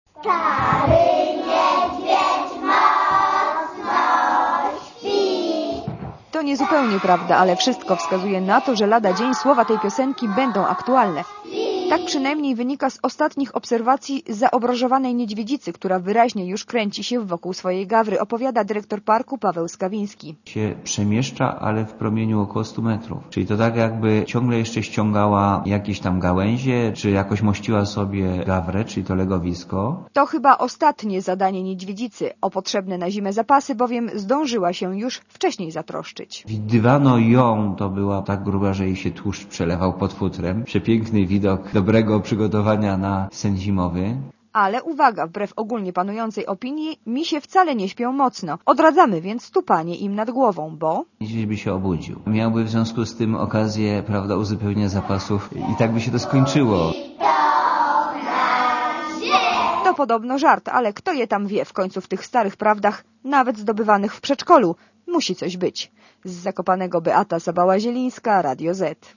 (RadioZet) Źródło: (RadioZet) Komentarz audio Oceń jakość naszego artykułu: Twoja opinia pozwala nam tworzyć lepsze treści.